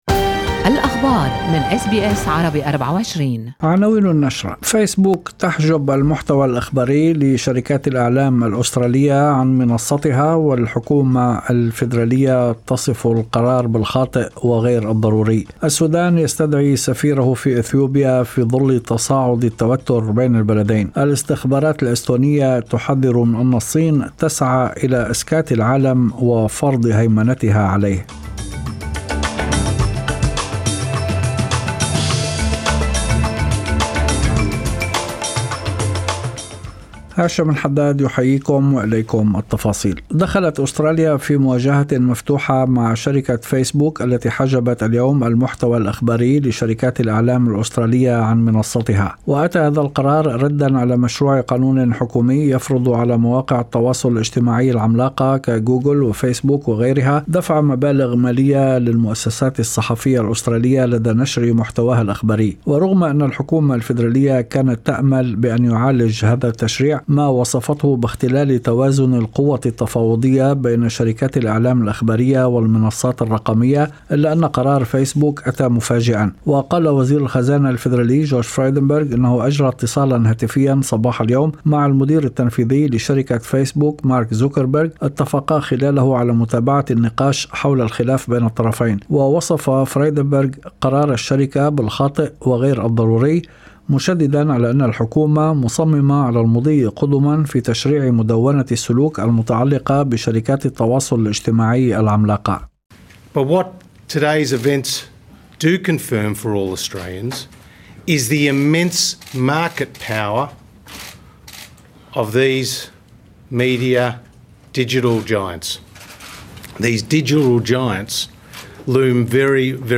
نشرة أخبار المساء 18/2/2021